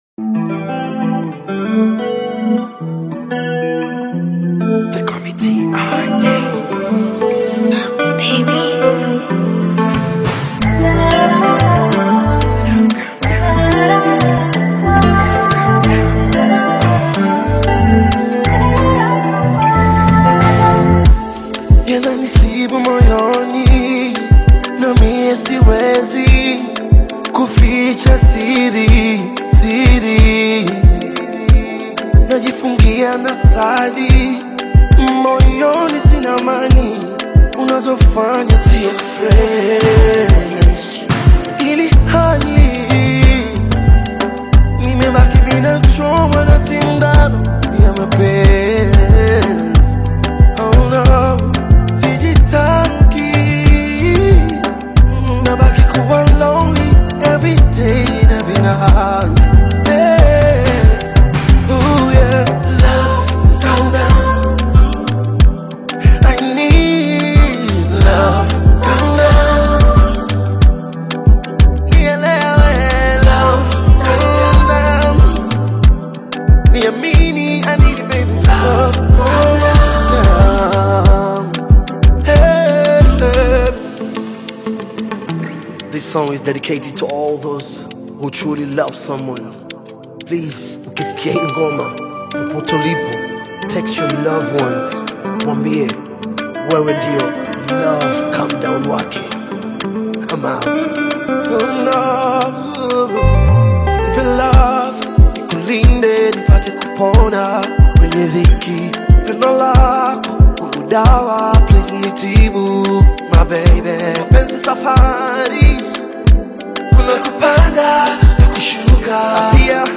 uplifting Tanzanian Afro-pop/Bongo Flava/R&B-inspired single